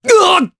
Crow-Vox_Damage_jp_03_b.wav